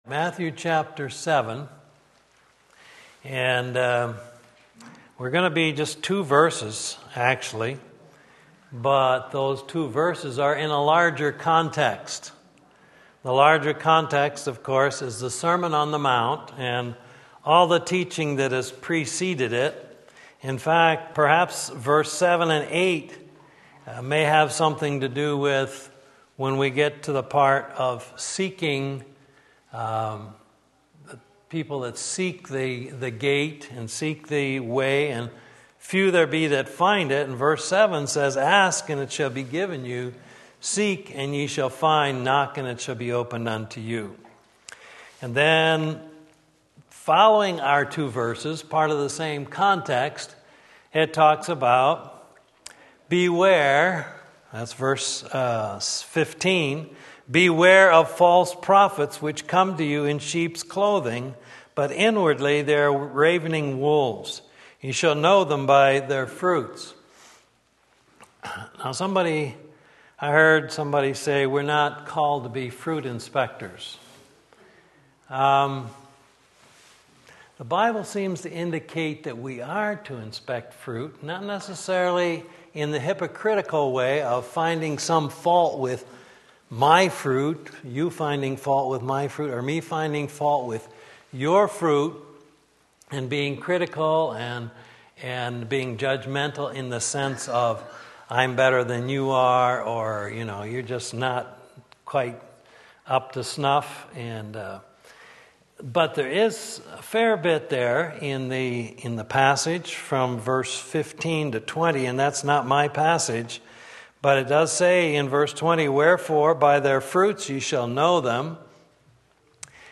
Two Gates and Two Ways Matthew 7:13-14 Sunday School